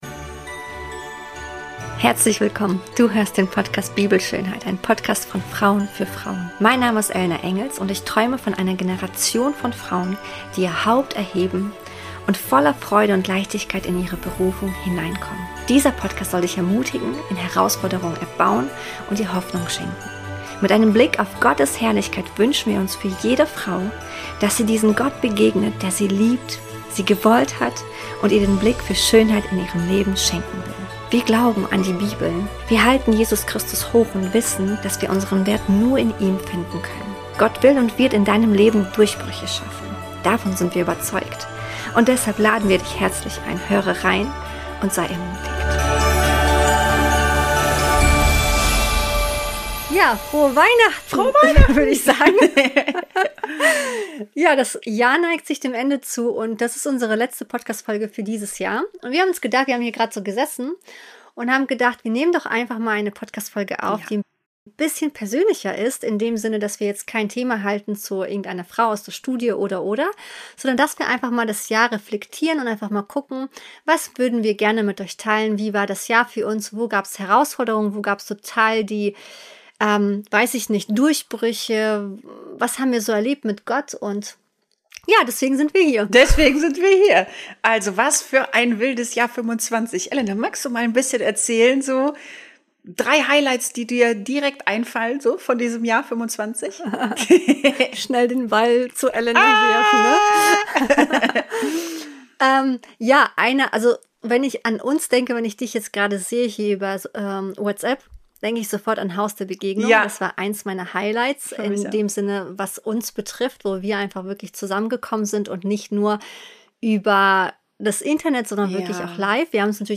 Wir haben diese Folge ohne Skript aufgenommen.
Wir haben uns einfach zusammengesetzt und miteinander geteilt, was uns bewegt hat.